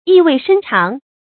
意味深長 注音： ㄧˋ ㄨㄟˋ ㄕㄣ ㄔㄤˊ 讀音讀法： 意思解釋： 含意深刻；耐人尋味。